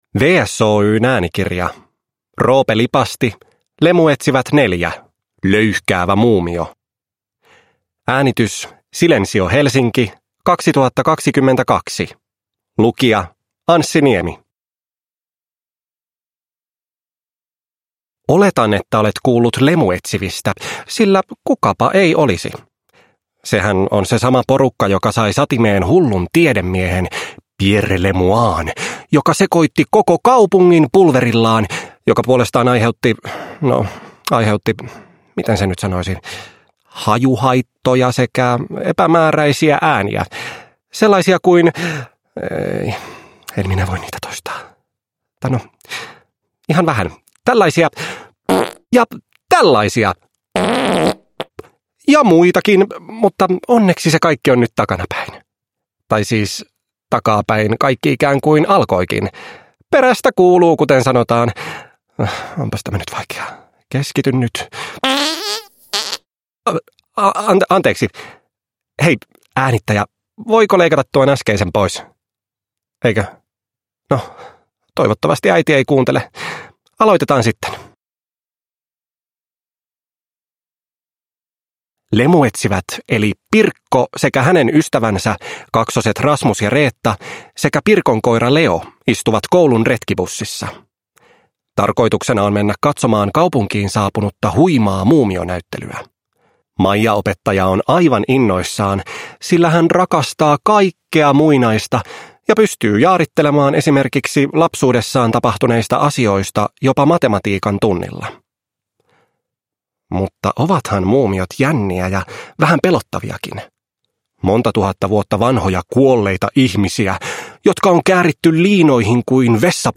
Lemuetsivät 4: Löyhkäävä muumio (ljudbok) av Roope Lipasti